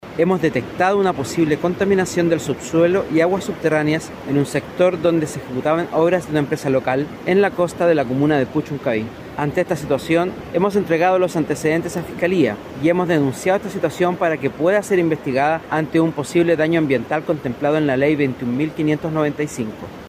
El delegado presidencial para la zona, Cristian Cáceres, confirmó que esta situación se debe a los trabajos realizados por una empresa local en el lugar, de la cual no se informó el nombre, además, aseguró que entregó los antecedentes a la Fiscalía para que se investigue una eventual violación a la Ley Ambiental.